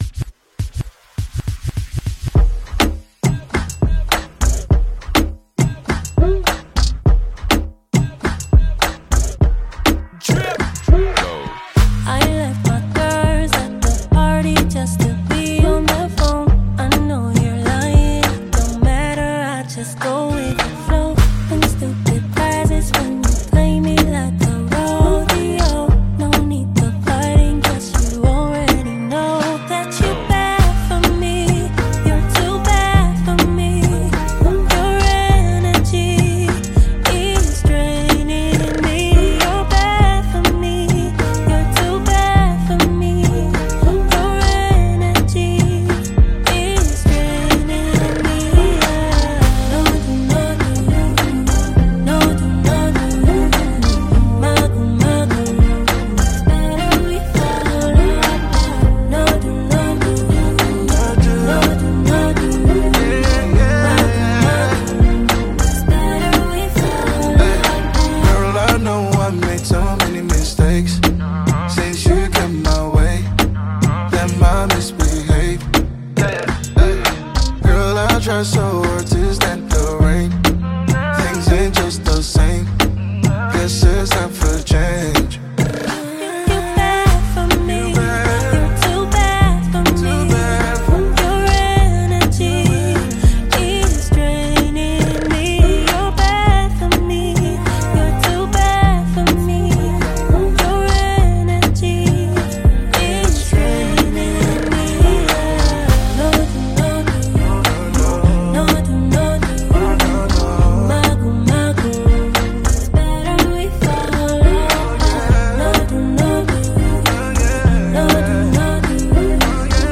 • Genre: Rnb